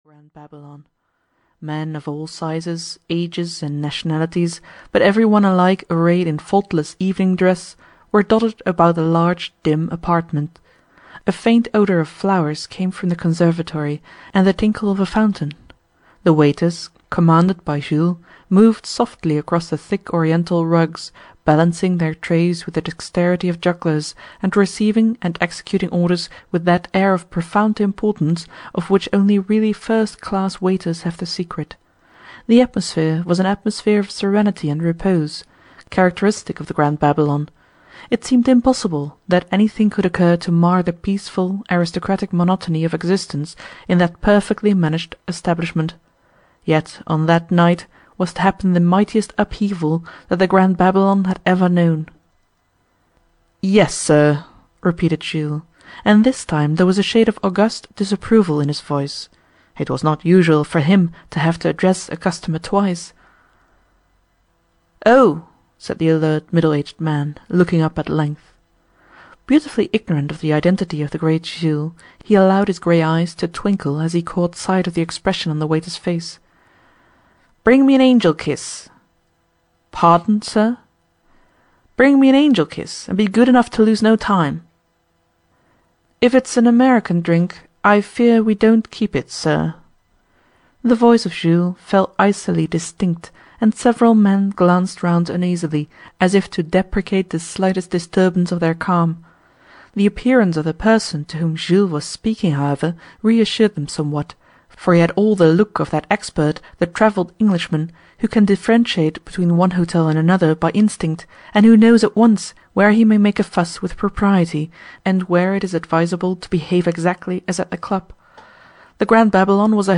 The Grand Babylon Hotel (EN) audiokniha
Ukázka z knihy